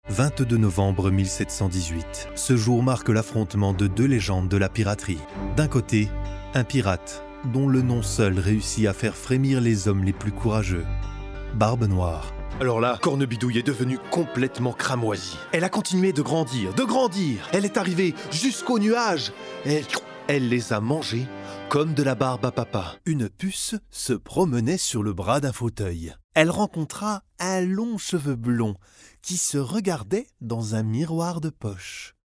Narration
Explainer Videos
I am a professional french voicer over from 5 years with a smooth young voice, with some pretty bass; i can easily add modulations on my voice.
Isolated Cabin